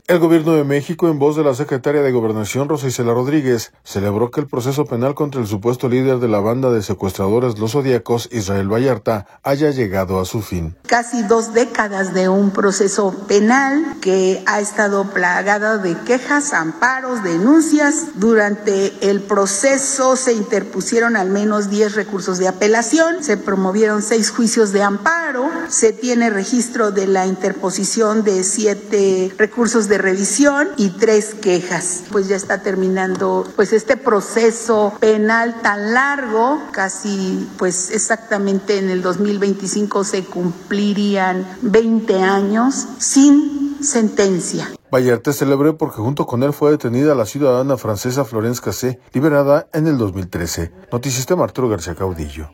El Gobierno de México, en voz de la secretaria de Gobernación, Rosa Icela Rodríguez, celebró que el proceso penal contra el supuesto líder de la banda de secuestradores Los Zodiacos, Israel Vallarta, haya llegado a su fin.